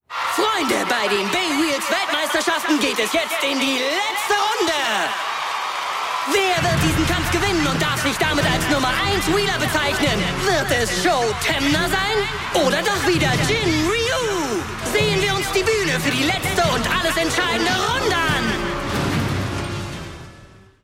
★ Rolle: Ansager